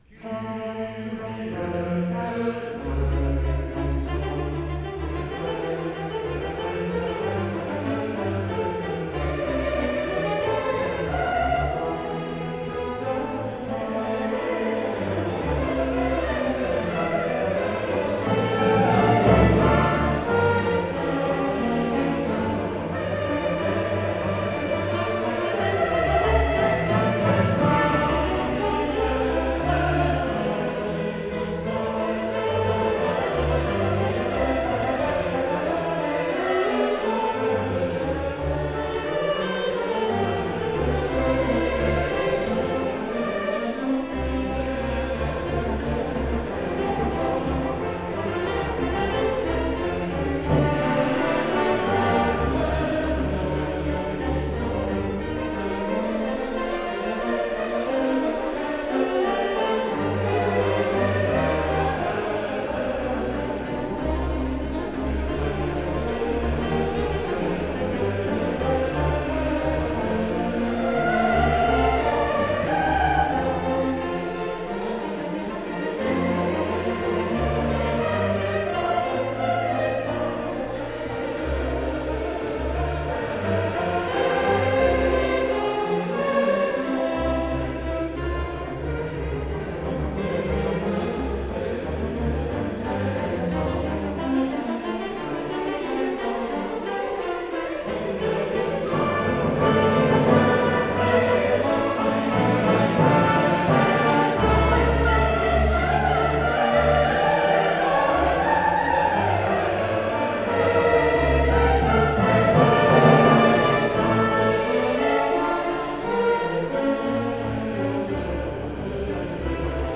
4:27 Mozart Requiem I. Introitus Cornerstone Chorale, recorded live on May 11th, 2014 at Holyrood Church 2:37 Mozart Requiem II. Kyrie Cornerstone Chorale, recorded live on May 11th, 2014 at Holyrood Church